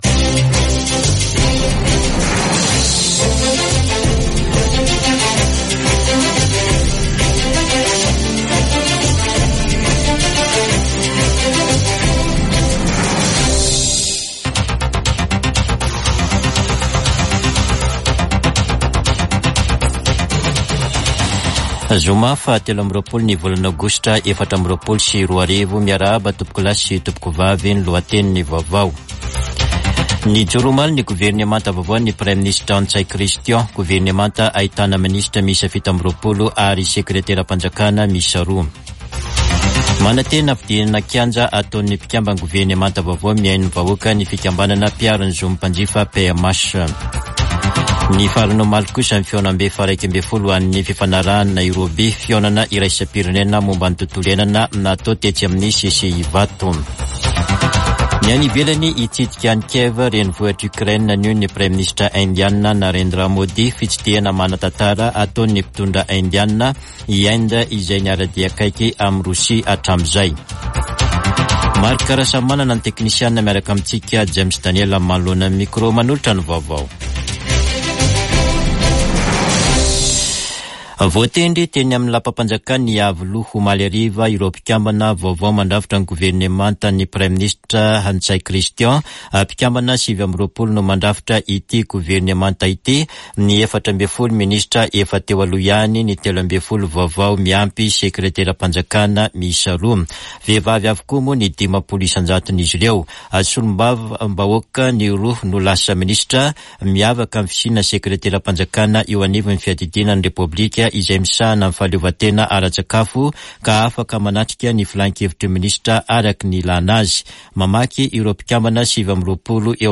[Vaovao maraina] Zoma 23 aogositra 2024